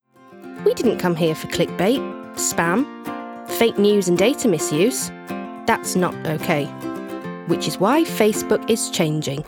RP ('Received Pronunciation')
Commercial, Bright, Friendly